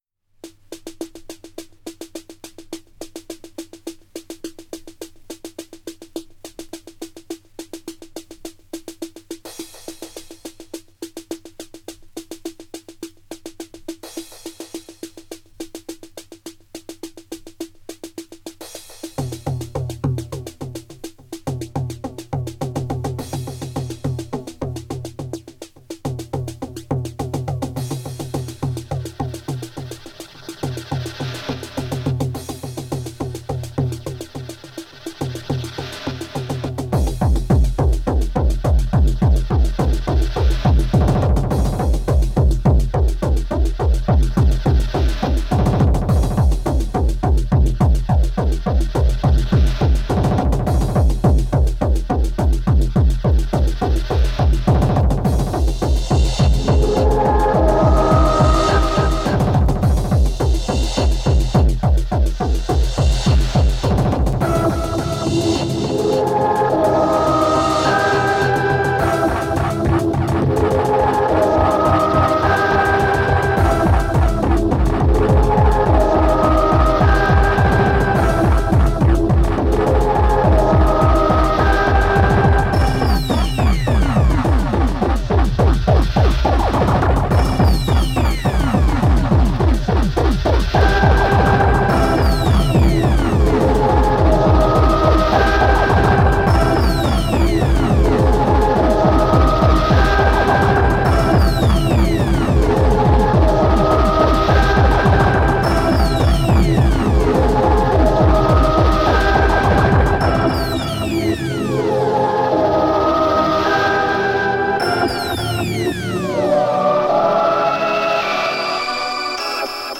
( 210bpm )